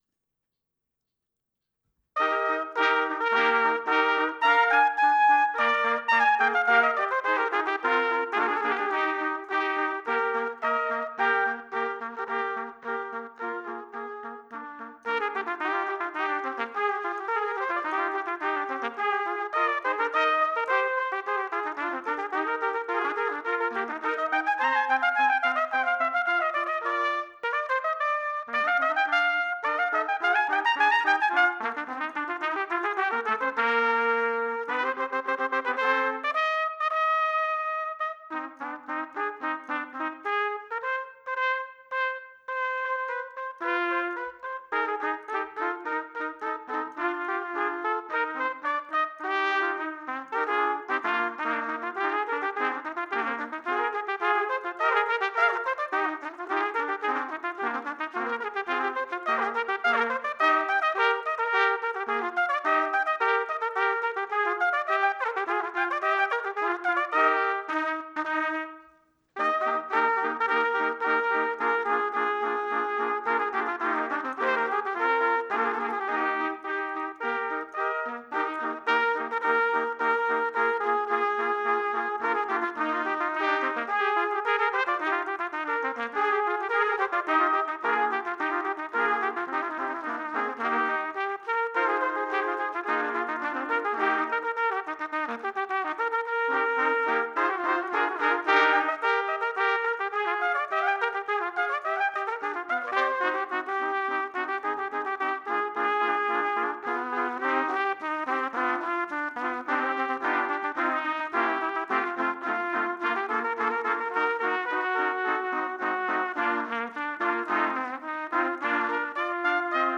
Although I prepared as well as I did, some passages were still beyond my current ability.